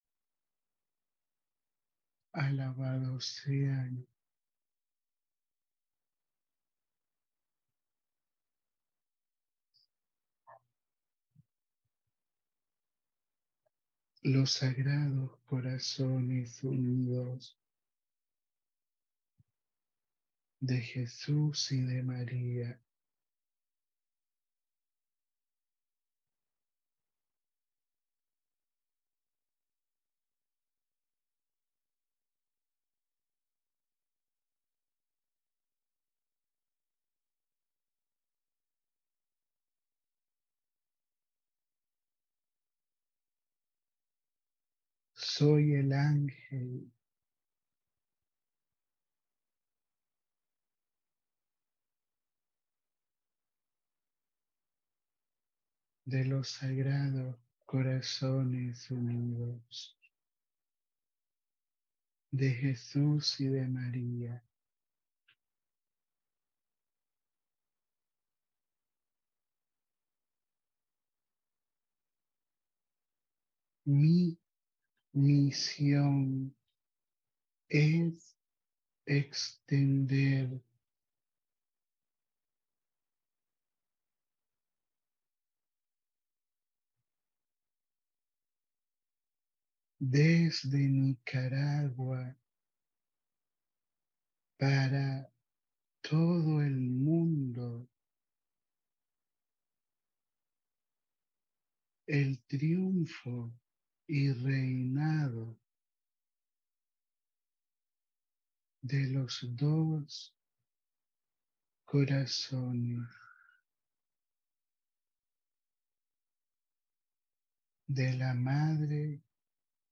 Audio da Mensagem